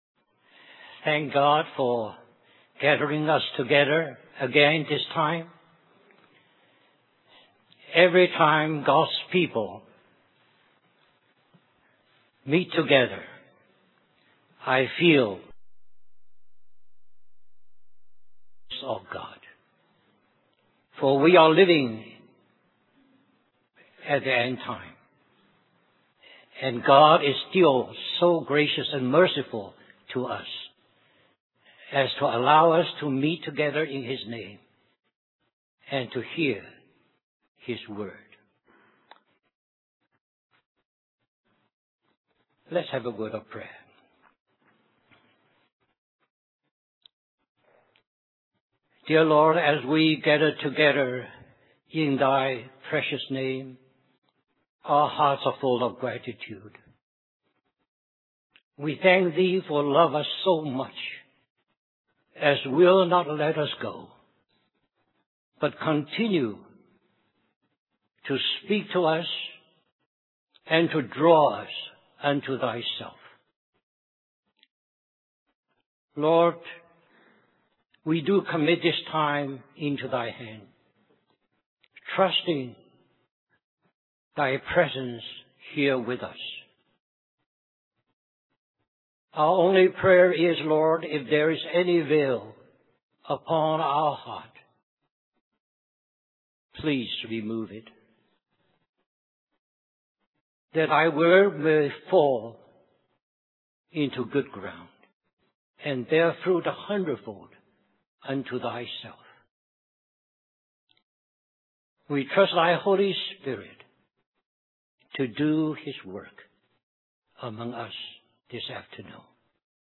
Christian Family Conference
Message